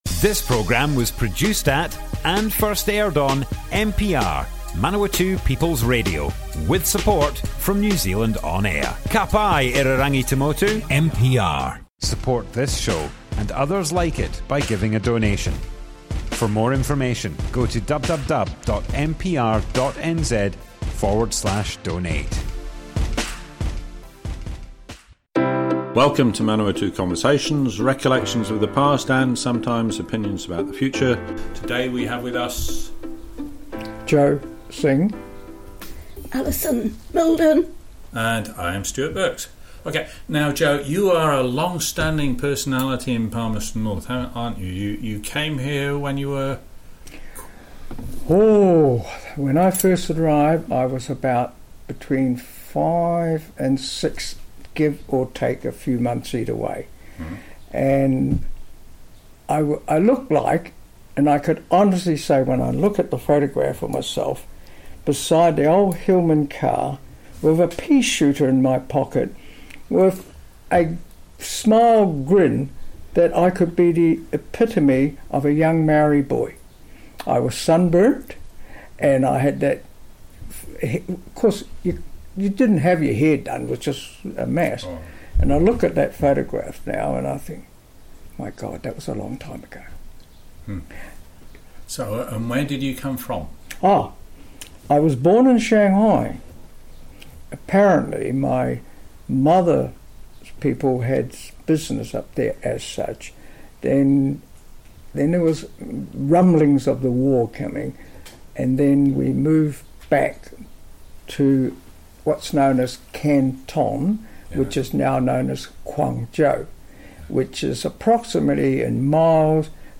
Manawatu Conversations Object type Audio More Info → Description Broadcast on Manawatu People's Radio, 16th February 2021.
oral history